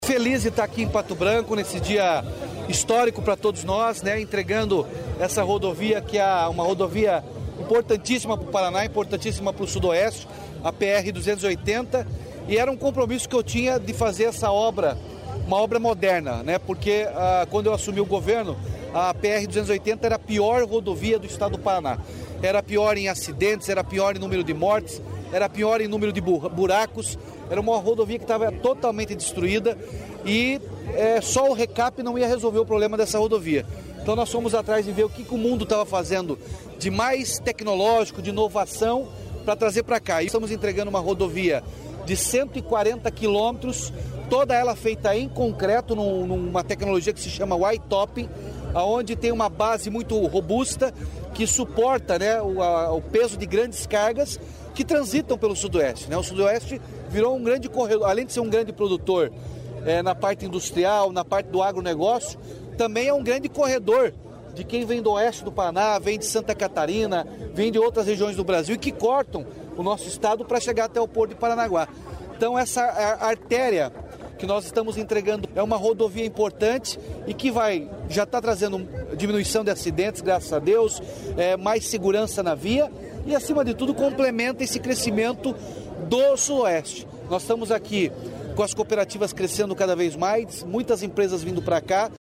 Sonora do governador Ratinho Junior sobre modernização da PRC-280